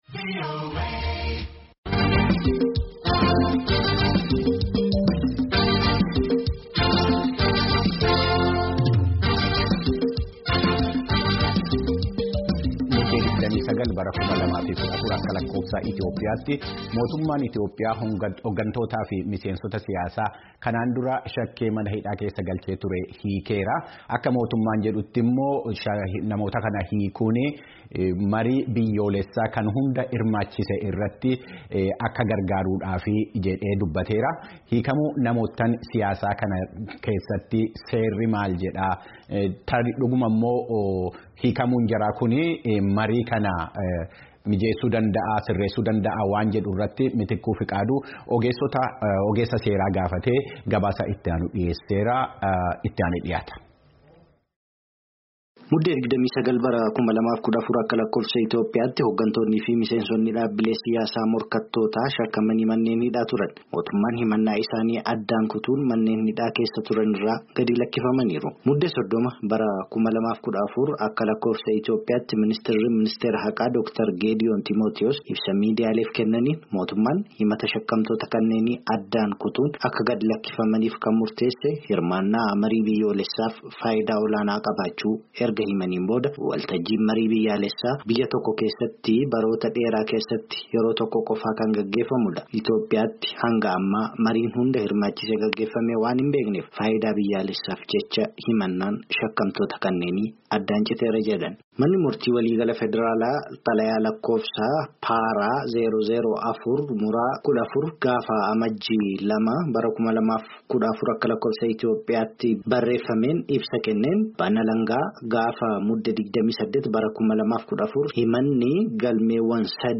ogeessoota seeraa lama dubbisuun